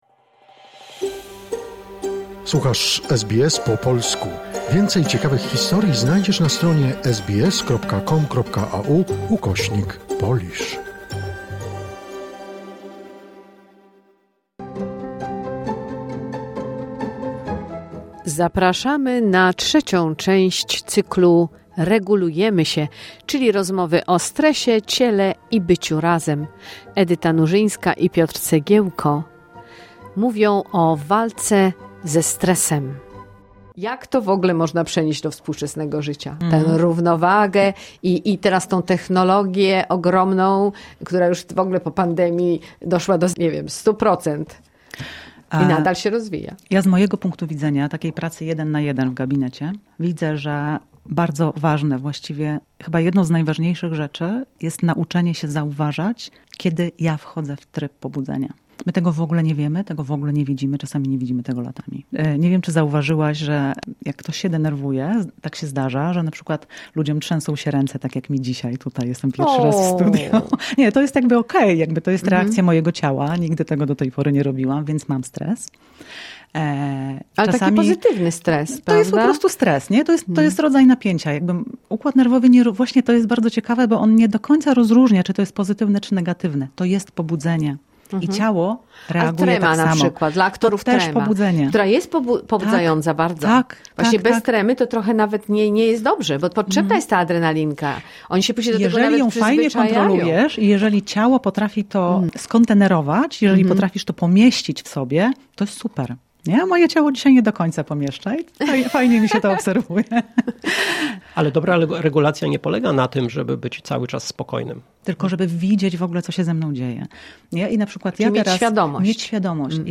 w studio SBS